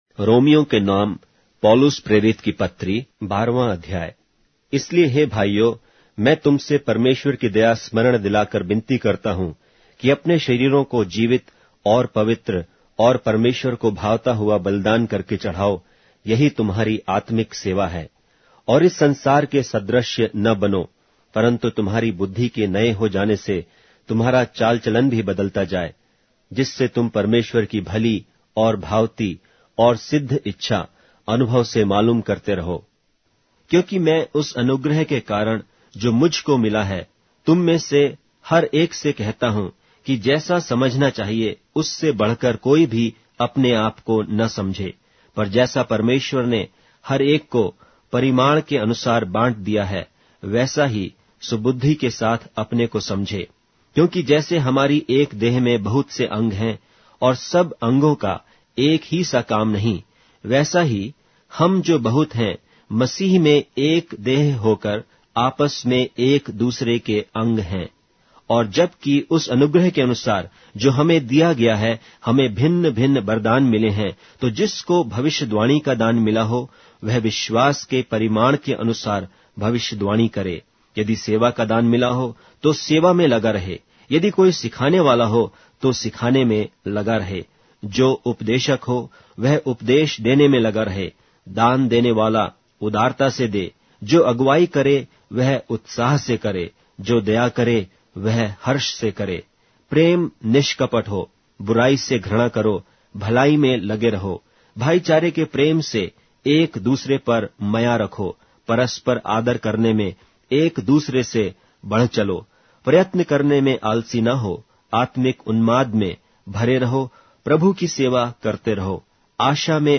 Hindi Audio Bible - Romans 3 in Nlv bible version